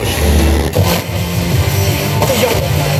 80BPM RAD3-L.wav